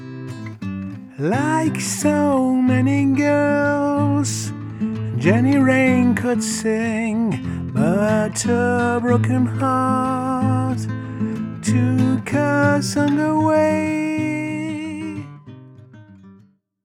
J'ai acheté un t bone sc 450 à coupler avec les akai j'ai fait quelques test vite fait, un court extrait du son et j'en profite ,j'essaye un nouveau plugin,
Il me semble que le B descend plus bas, mais ne manque pas d'aigu.